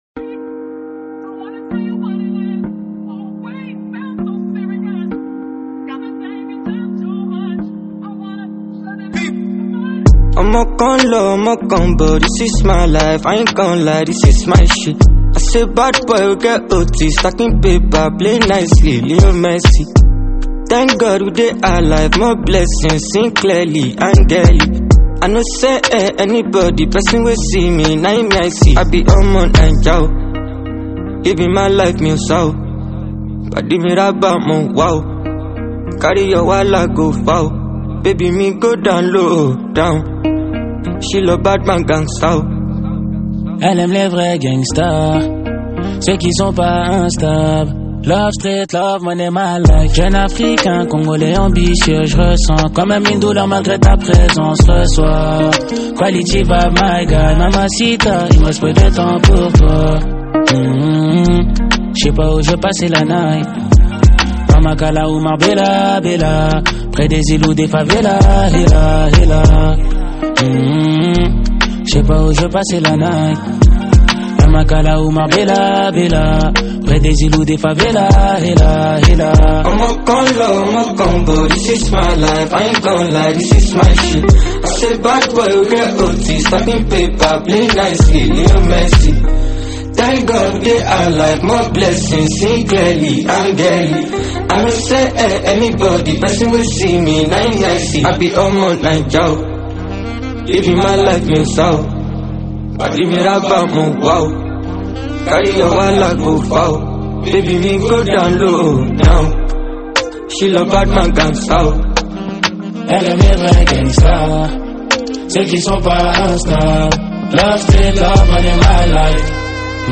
| Afrobeat